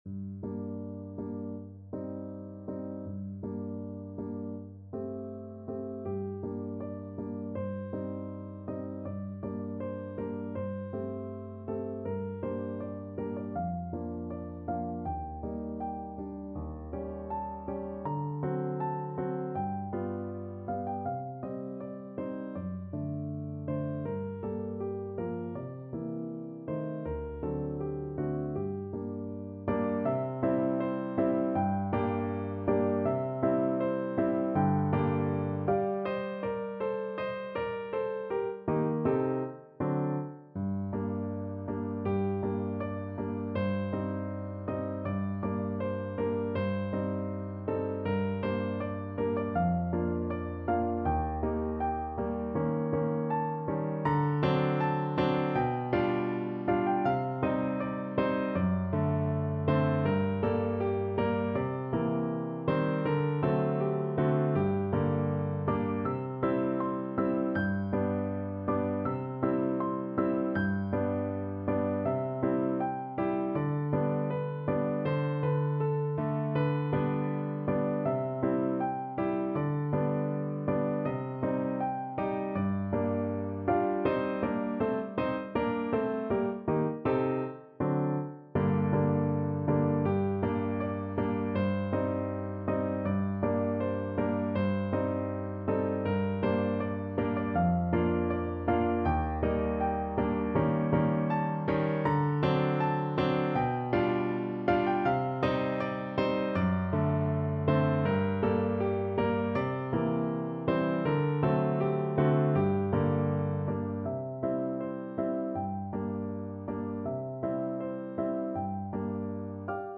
No parts available for this pieces as it is for solo piano.
G minor (Sounding Pitch) (View more G minor Music for Piano )
2/4 (View more 2/4 Music)
~ = 100 Andante
Piano  (View more Advanced Piano Music)
Classical (View more Classical Piano Music)
jarnefelt_berceuse_PNO.mp3